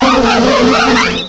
cry_not_vespiquen.aif